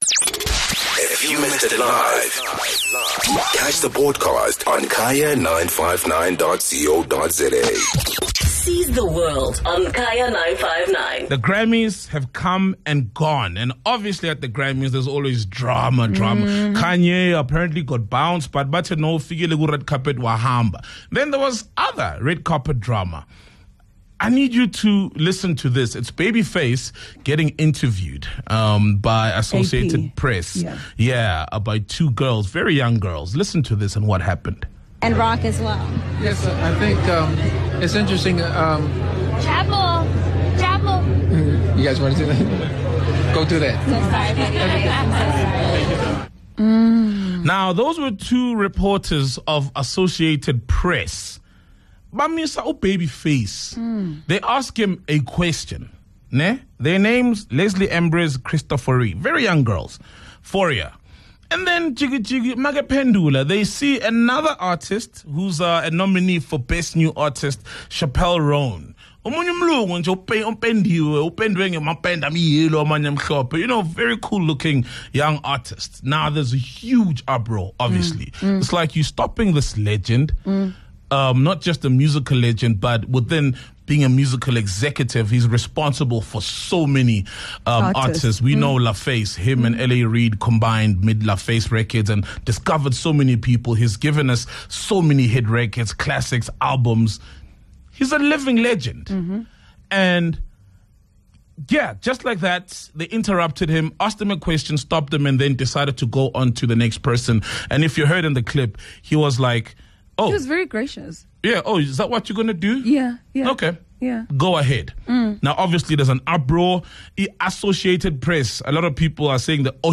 The team and listeners held a discussion about how older artists get treated once new ones crop up.